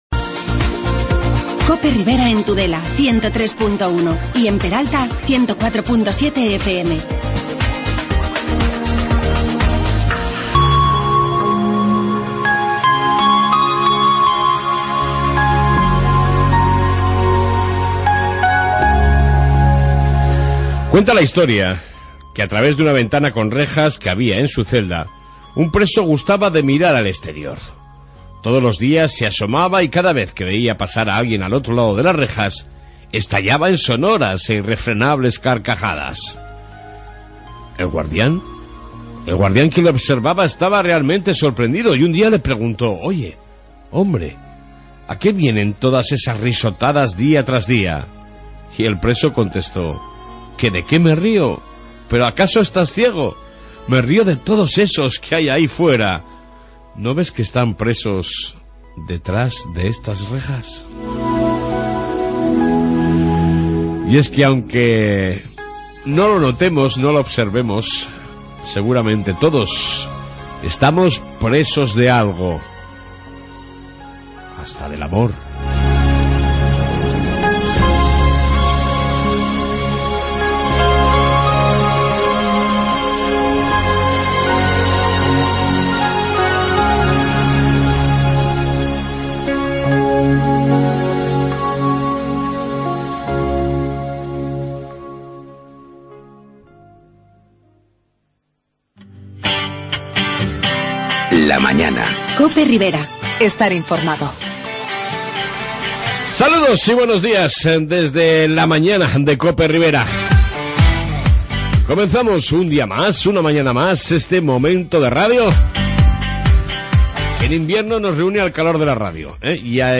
AUDIO: Reflexión y entrevista con Maribel echave ante la próxima despedida como Presidenta de la Mancomunidad de la ribera